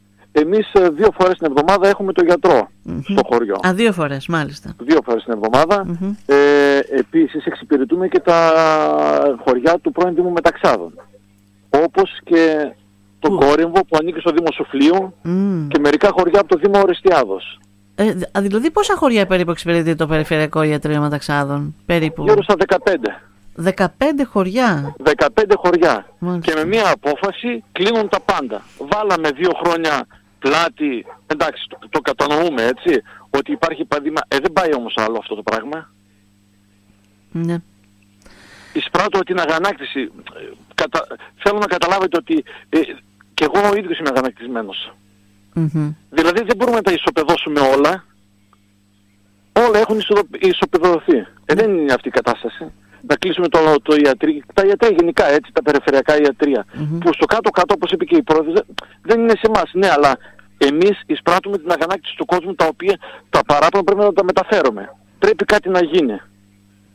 Η αγανάκτηση είναι έκδηλη,  στις δηλώσεις της προέδρου της Δημοτικής ενότητας Διδυμοτείχου Ευαγγελίας Καραγιάννη  και του προέδρου της κοινότητας Μεταξάδων Χρήστου Κισσούδη  που συνυπογράφουν την επιστολή.
Μίλησαν σήμερα στην ΕΡΤ Ορεστιάδας παρουσιάζοντας τα προβλήματα που δημιουργεί μια τέτοια απόφαση και τα παράπονα που εισπράττουν καθημερινά από κατοίκους που σωματικά και οικονομικά είναι ανήμποροι να έρθουν στην πόλη.